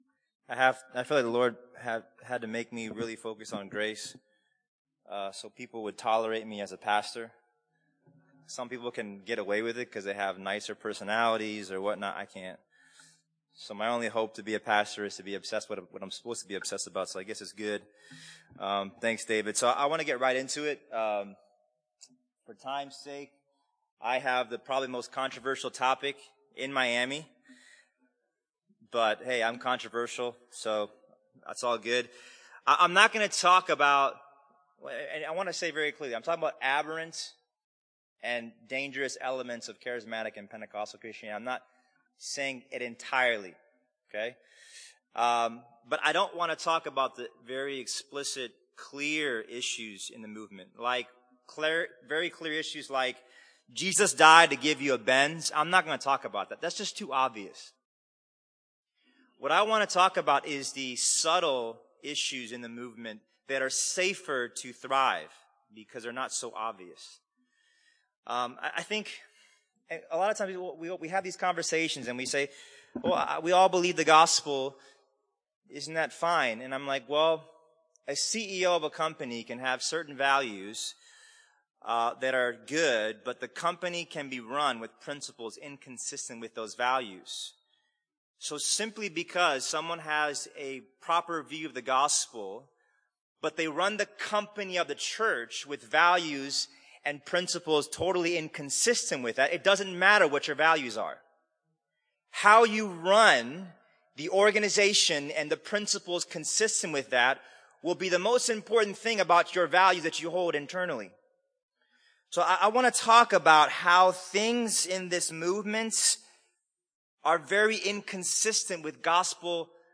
Four sessions were offered at this conference: